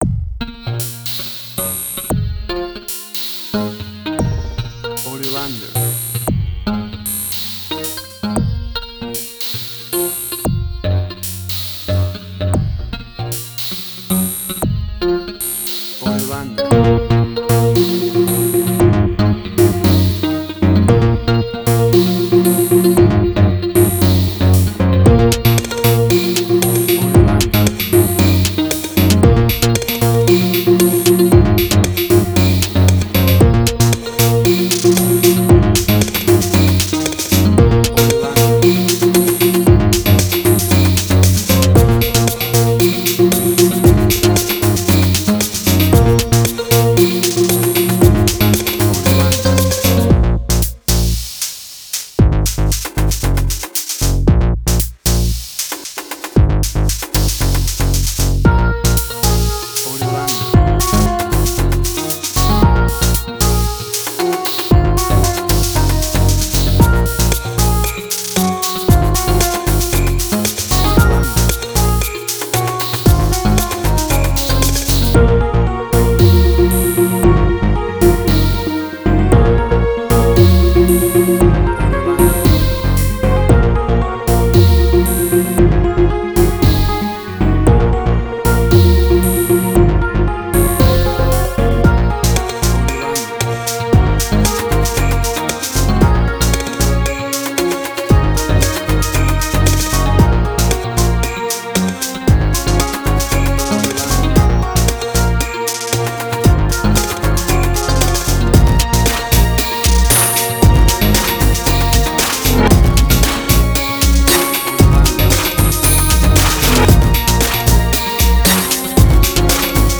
IDM, Glitch.
WAV Sample Rate: 16-Bit stereo, 44.1 kHz
Tempo (BPM): 115